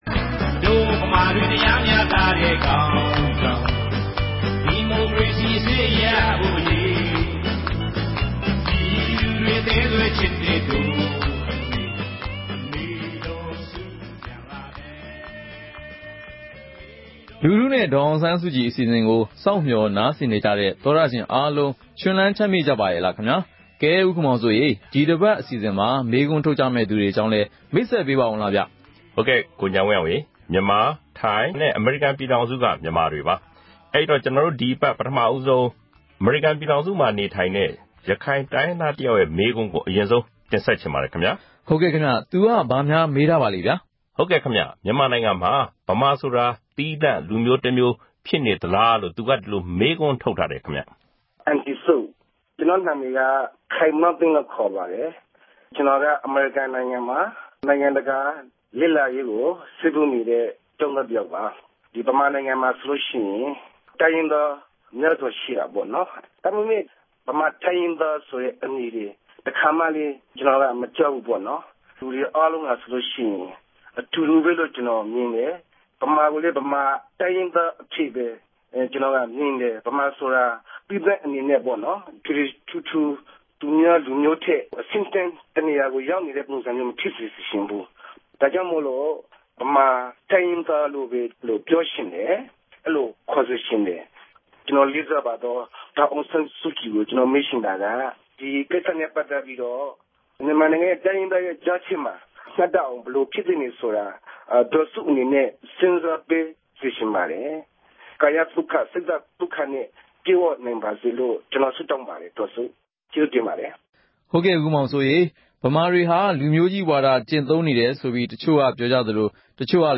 ‘လူထုနှင့် ဒေါ်အောင်ဆန်းစုကြည်’ အပတ်စဉ်အမေးအဖြေ အစီအစဉ်
ဒီ အစီအစဉ်ကနေ ပြည်သူတွေ သိချင်တဲ့ မေးခွန်းတွေကို ဒေါ်အောင်ဆန်းစုကြည် ကိုယ်တိုင် ဖြေကြားပေးမှာ ဖြစ်ပါတယ်။
အဲဒီ တယ်လီဖုန်း နံပါတ်ကို RFA က ဆက်သွယ်ပြီး ကာယကံရှင်ရဲ့ မေးမြန်းစကားတွေကို အသံဖမ်းယူကာ ဒေါ်အောင်ဆန်းစုကြည်ရဲ့ ဖြေကြားချက်နဲ့အတူ ထုတ်လွှင့်ပေးမှာ ဖြစ်ပါတယ်။